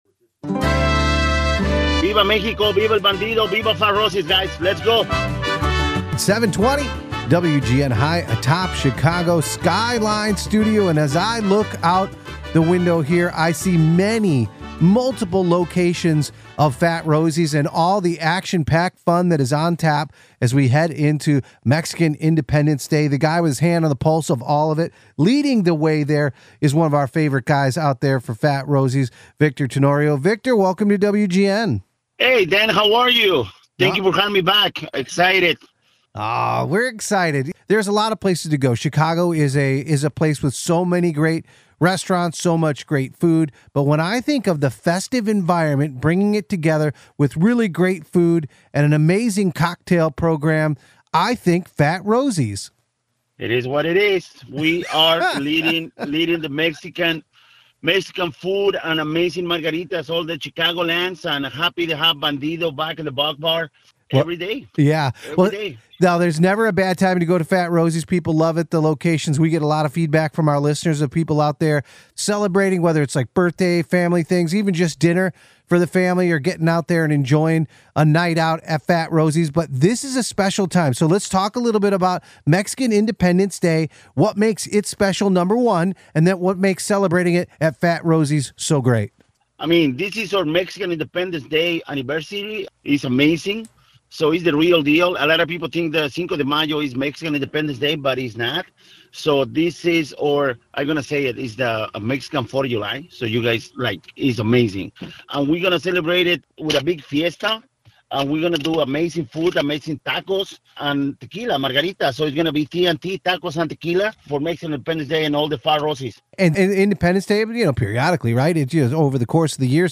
on WGN Radio